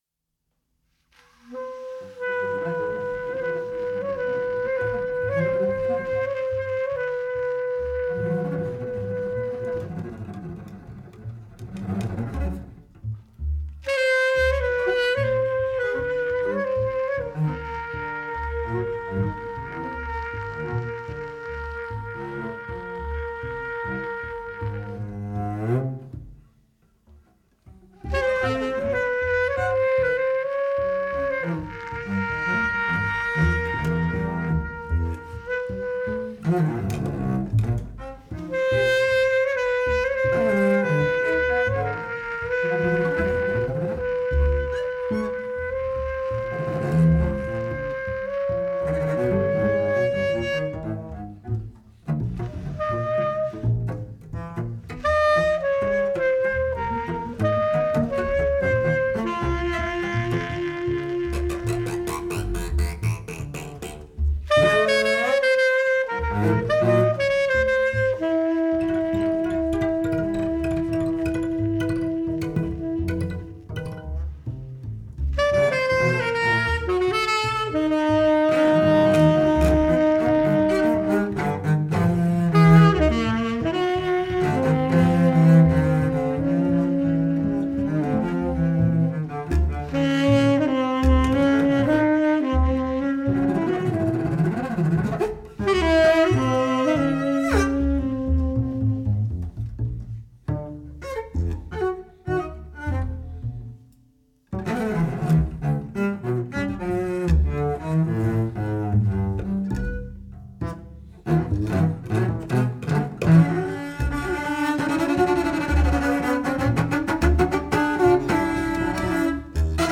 cello
double bass
tenor sax